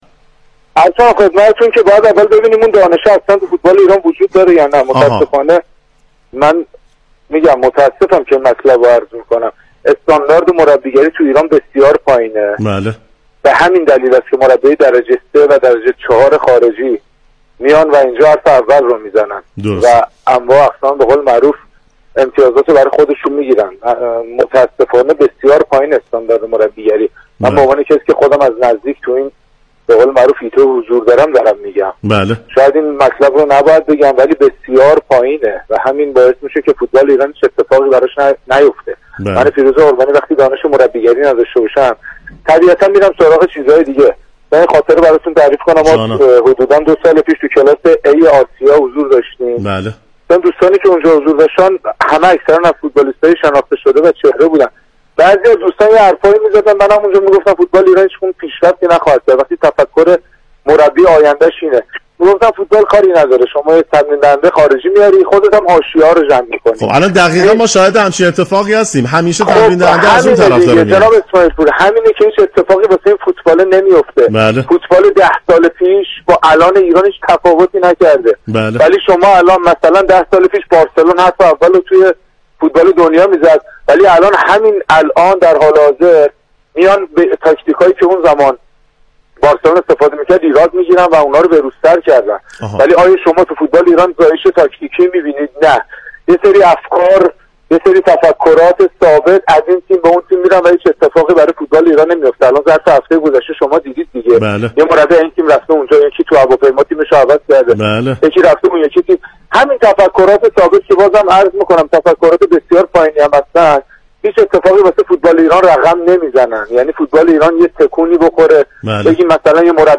از همین رو آفساید رادیو جوان، در گفتگویی تلفنی با مدافع سابق استقلال به بررسی این موضوع پرداخت.